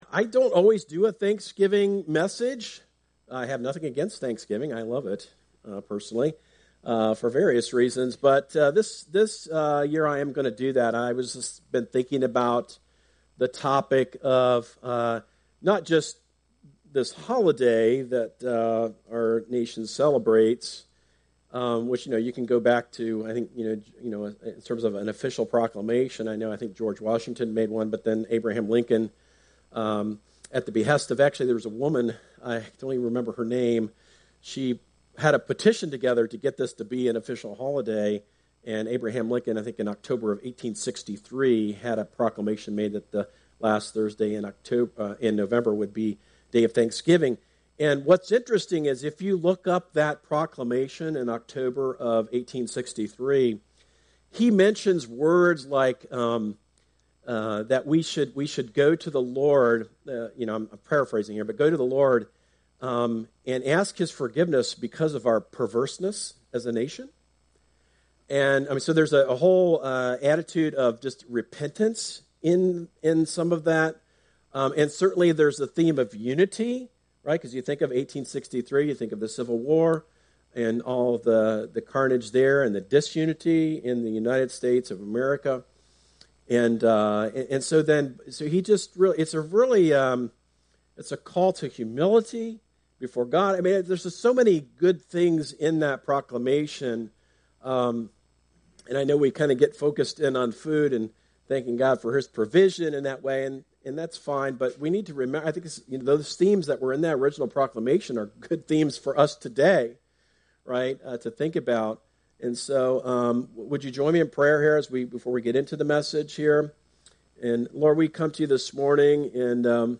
Stand Alone Sermons